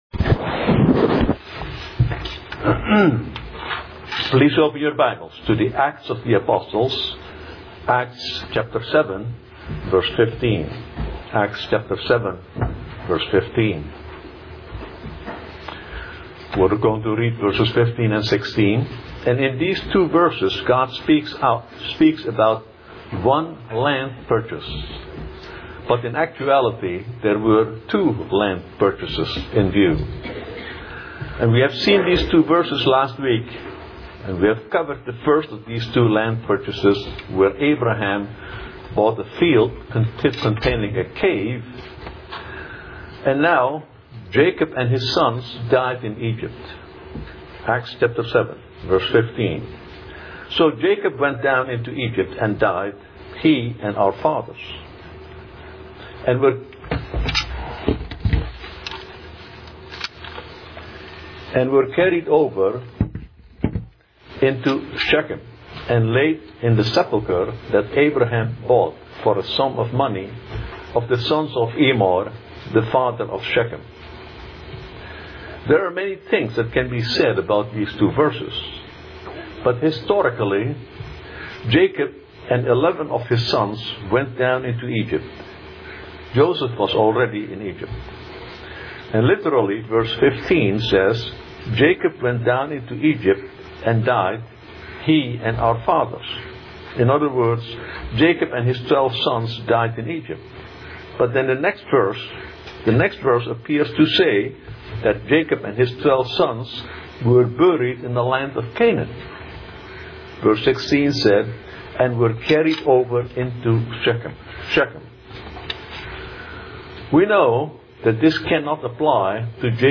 This is an audio sermon